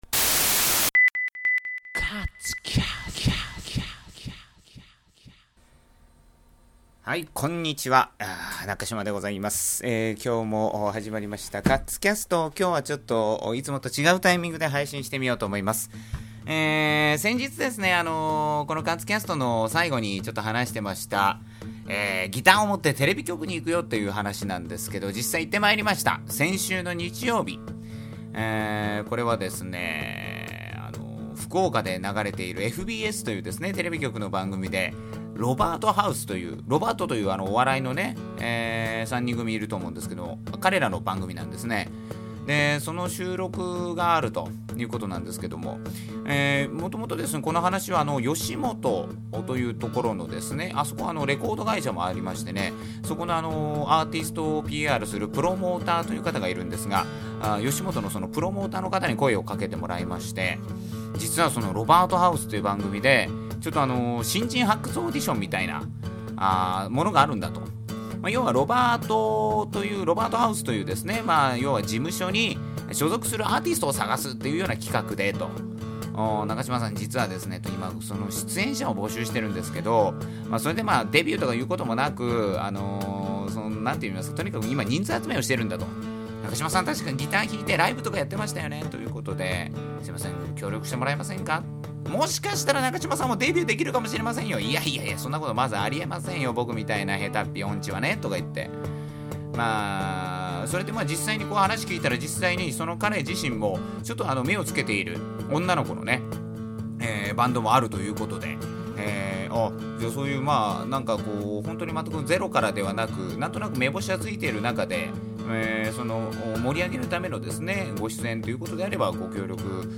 今回もヒトリキャスト。